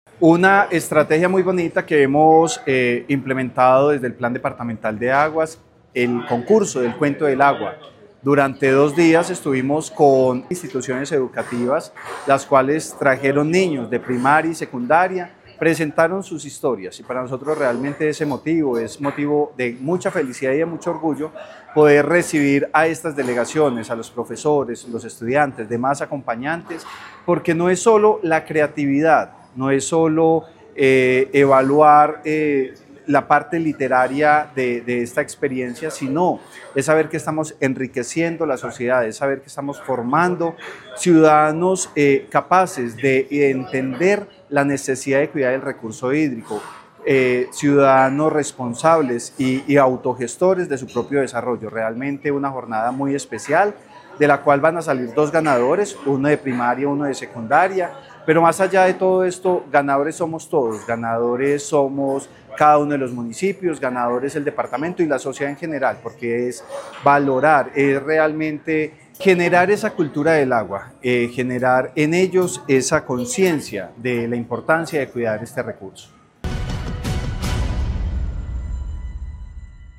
Secretario de Vivienda de Caldas, Francisco Vélez Quiroga.